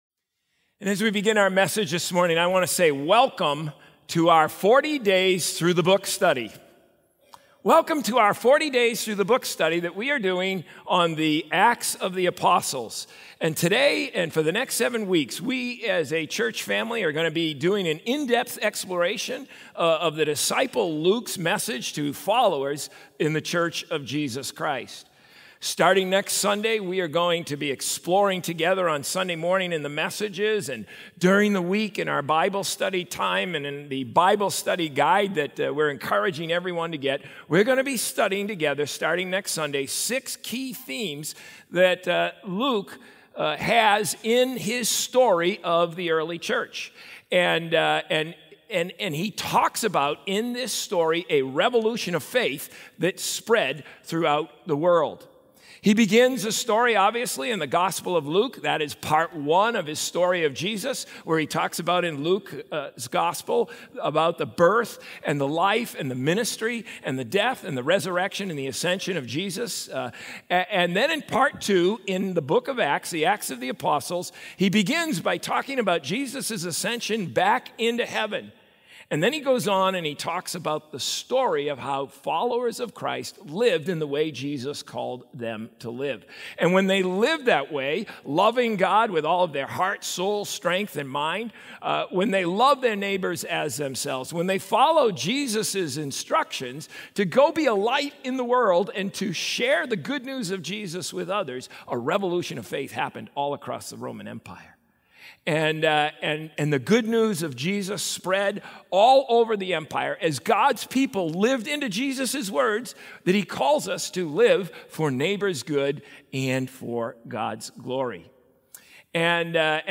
Sermons | COMMUNITY Covenant Church